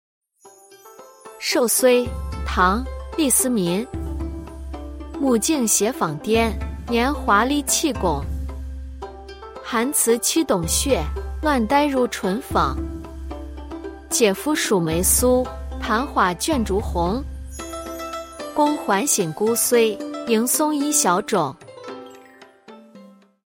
中原官话（陕西）朗诵唐朝李世民的《守岁》：
中原官话（陕西）
中原官话（陕西）.mp3